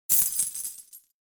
Added some sound effects